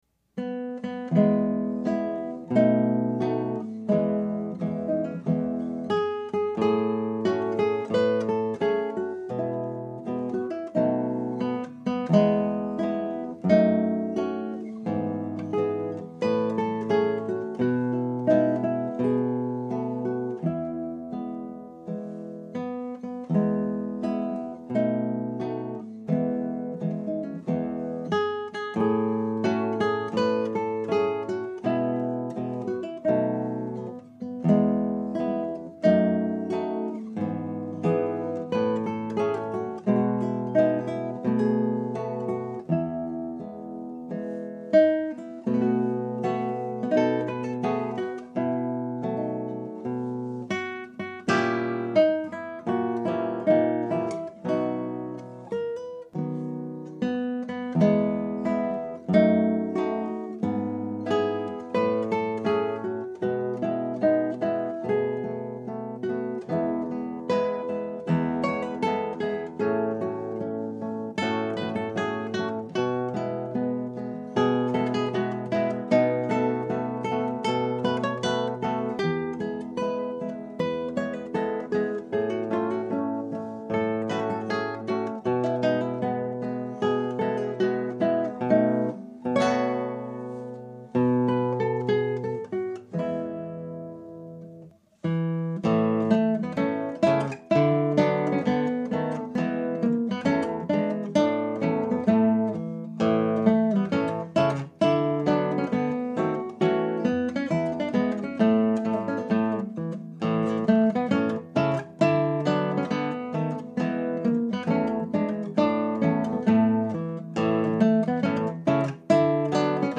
Scraps from the Operas arranged for Two Guitars
Scrap 1: Andantino.
Scrap 2 (1:35): Marcia.
In this arrangement the transition is seamless, but in the opera this scrap is separated from the previous Druid chorus.